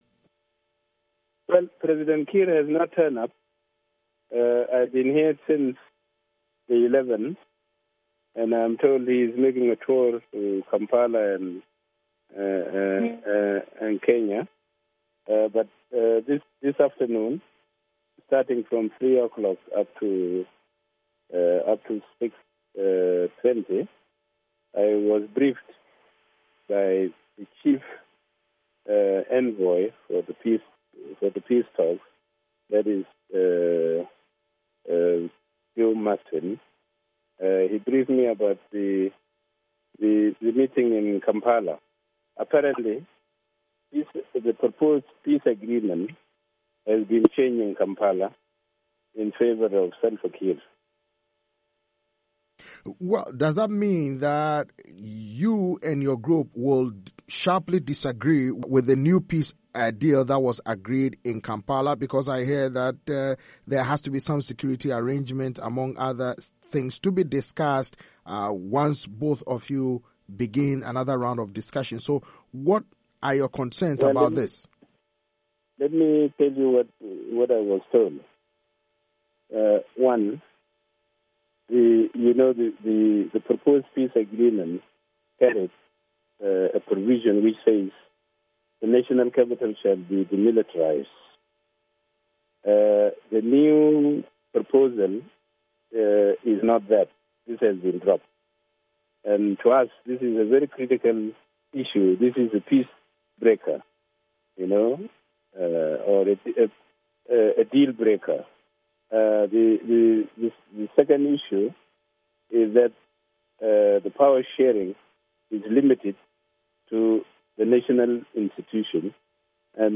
In an interview with VOA, Machar blames Ugandan President Yoweri Museveni and Kiir for changing the text of proposals previously agreed to by both parties, ahead of the resumption of the peace talks.